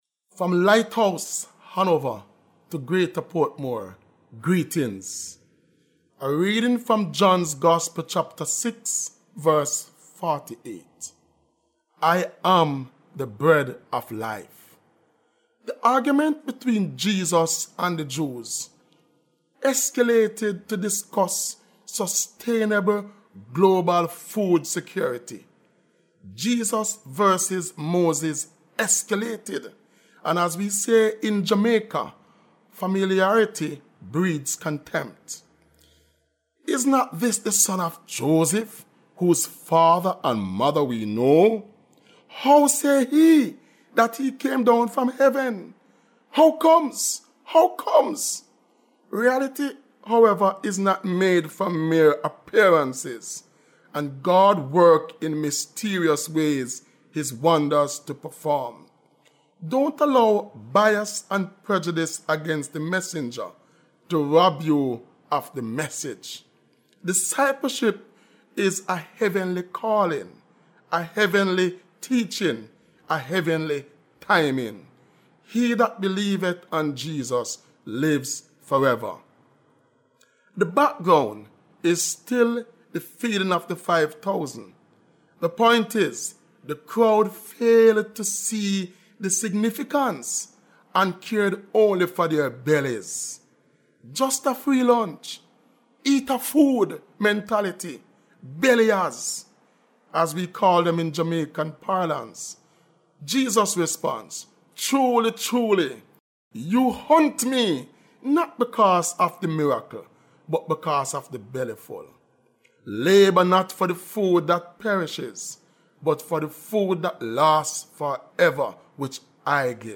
Grace Hour Broadcast